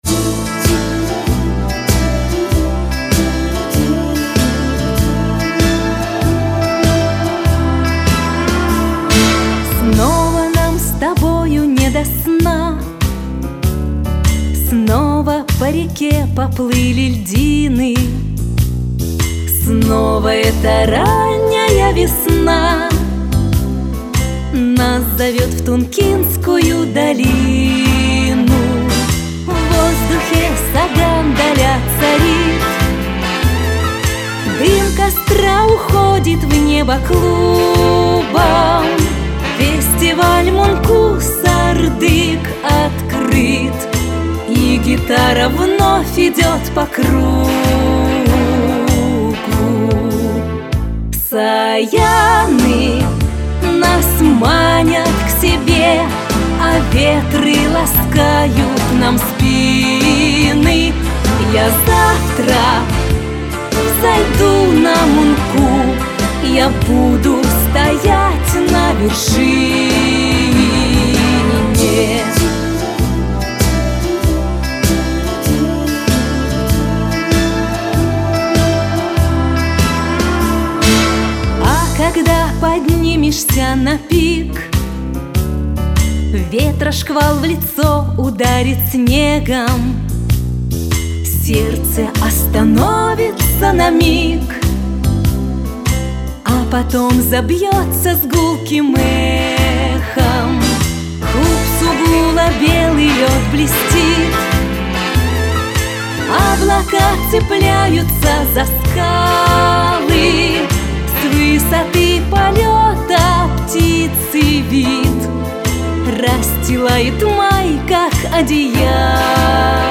Исполнение этих стихов под музыку